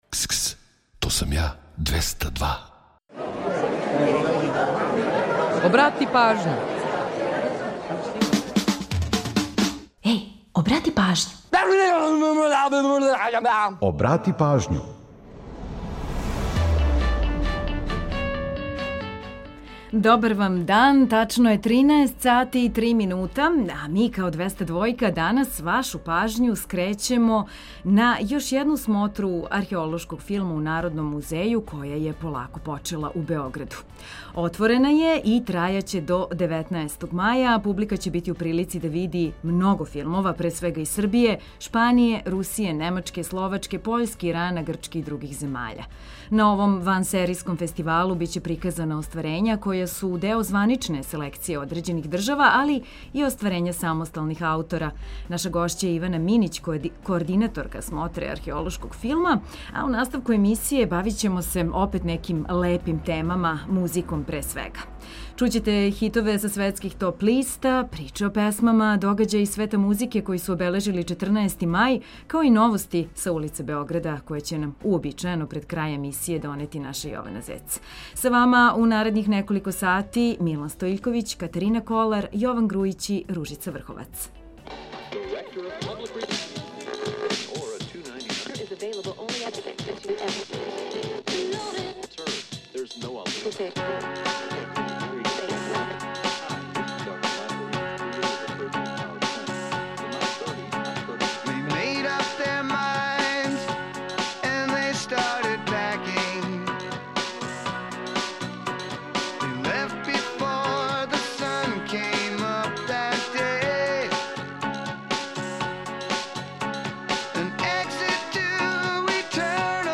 Чућете хитове са светских топ листа, „Приче о песмама”, догађаје из света музике који су обележили 14. мај као и новости са улица Београда.